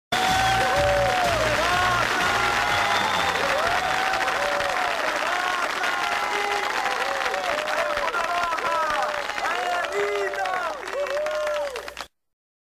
Aplausos
aplausos.mp3